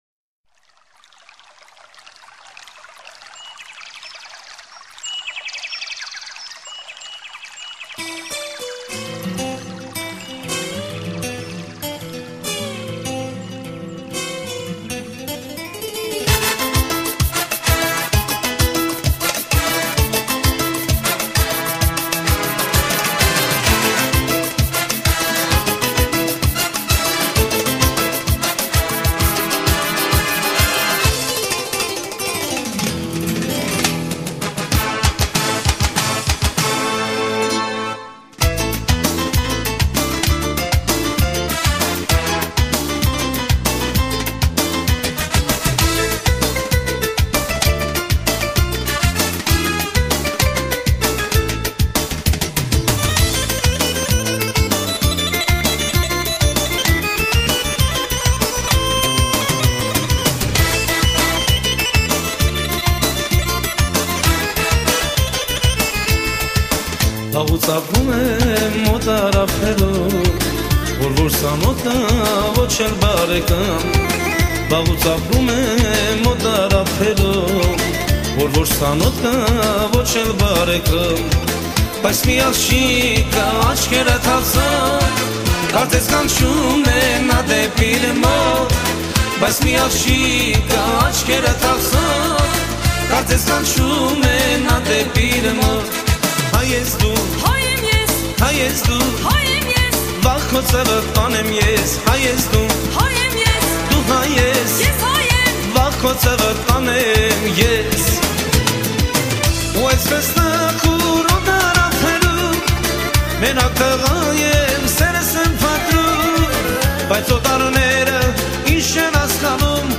96г. веселая